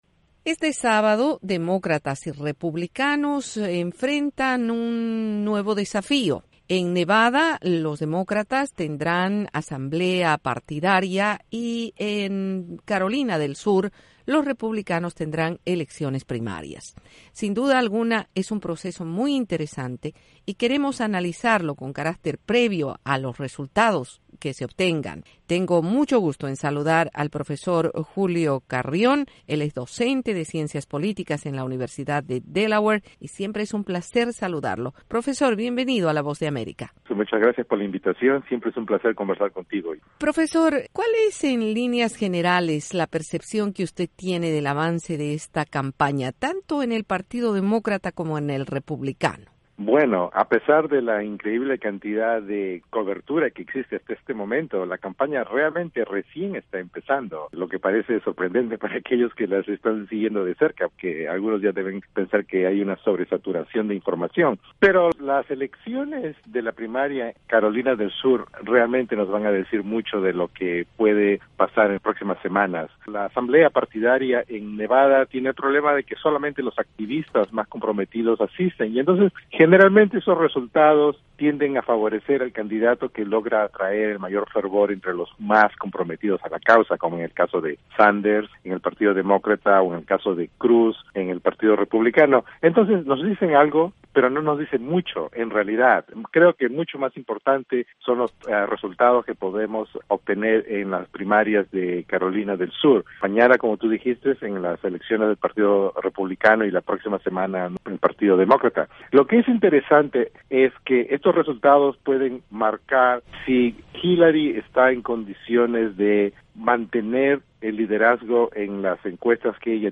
Entrevista con el analista politico